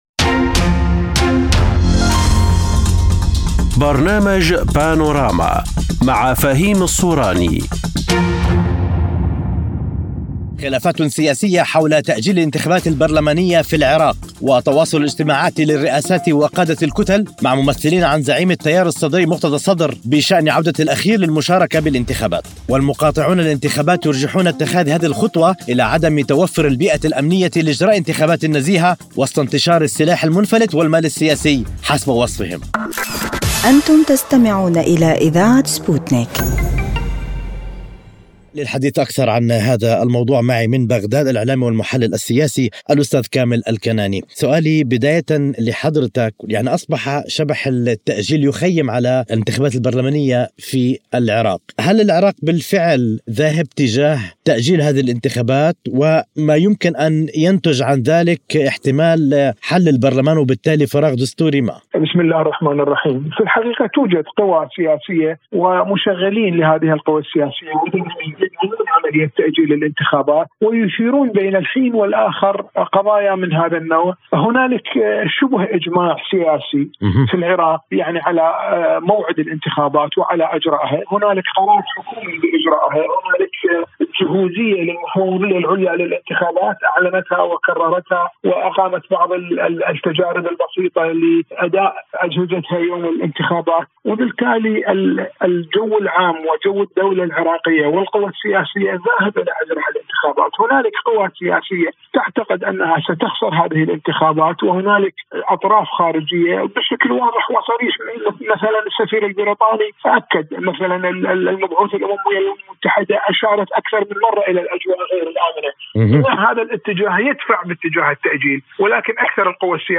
في حوار عبر “بانوراما”